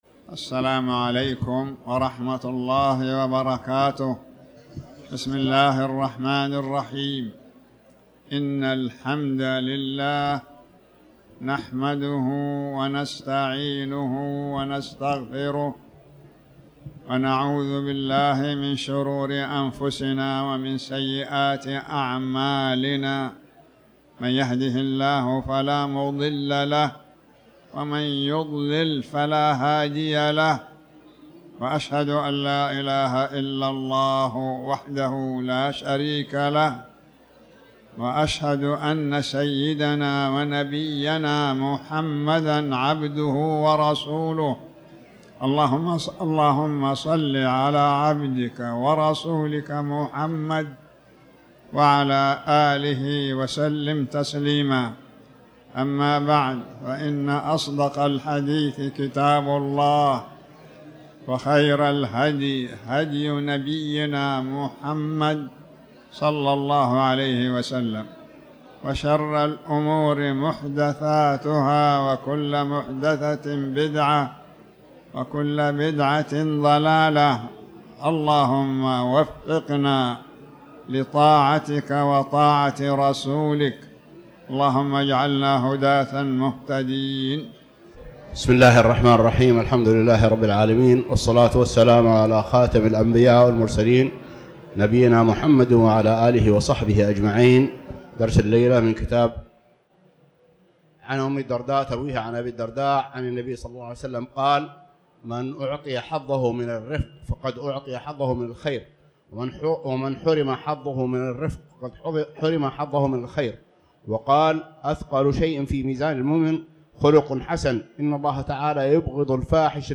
تاريخ النشر ٢٦ رجب ١٤٤٠ هـ المكان: المسجد الحرام الشيخ